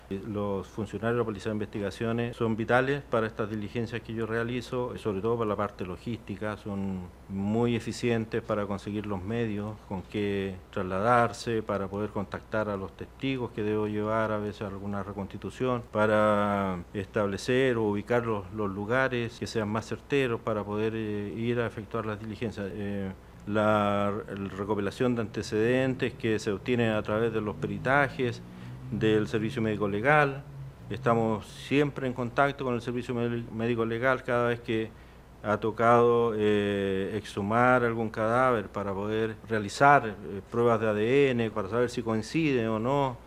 Gran interés concitó el conversatorio “Aspectos relevantes de la investigación de causas sobre violación a los derechos humanos”, organizada por la Corte de Apelaciones de La Serena en el marco de las actividades de conmemoración de su 174° aniversario.
17-08-cuna-ministro-Hormazabal.mp3